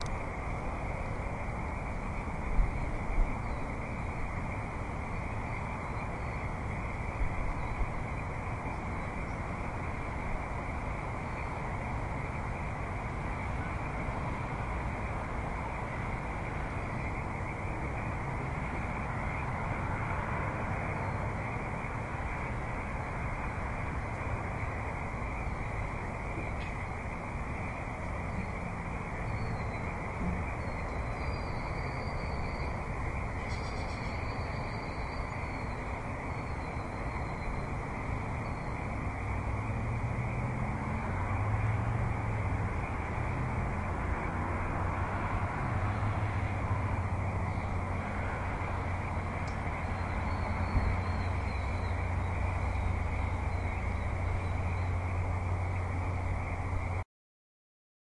描述：这是一张未经编辑的录音，名为Hamtramck，MI的伊斯兰教祈祷，每天在城市的几个清真寺播放几次。这个特别的祈祷是在午餐时间发生的Dhuhr，并且在距离扬声器仅约100英尺的Jos Campau和Caniff交叉口附近录制。我使用了Crown SASS立体声麦克风插入我的掌上电脑Tascam Dr 07.录音保持了96 K / 24位的原始发烧级音质。
标签： 伊斯兰教 声音效果 背景声 宗教 音景 哈姆特拉米克 通话-to-祷告 环境 背景 发现-声音 氛围 氛围 宣礼 现场记录 一般噪声 气氛
声道立体声